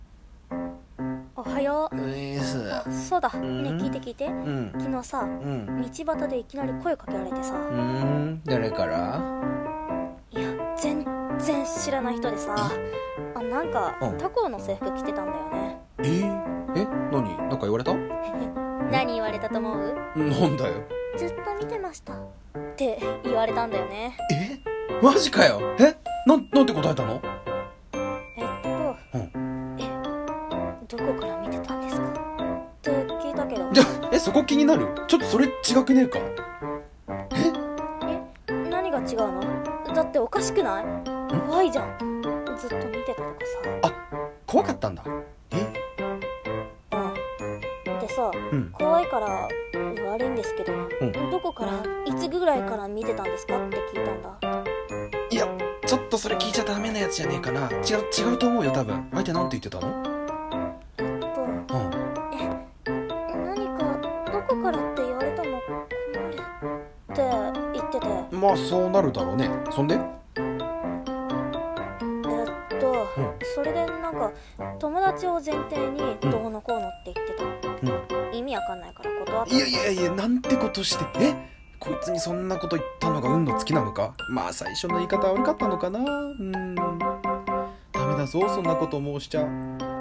ギャグ声劇】告られた？